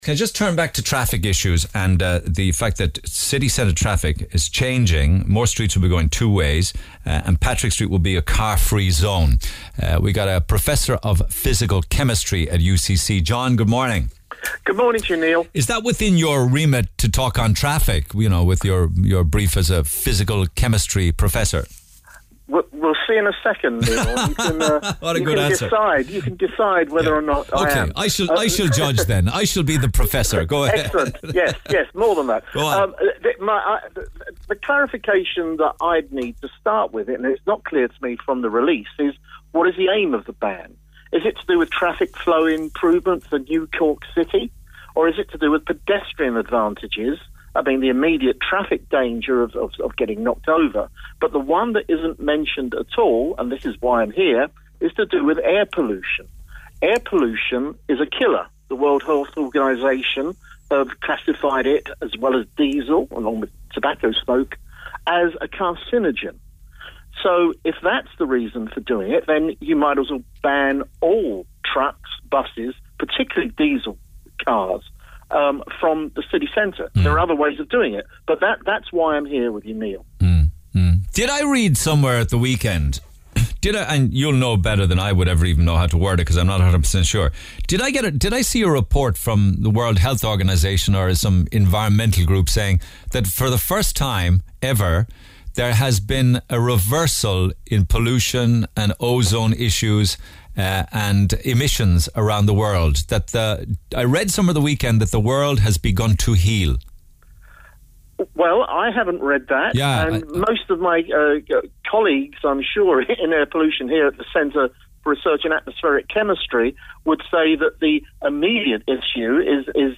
Radio Discussion on Congestion Charges